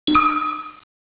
menu_select.wav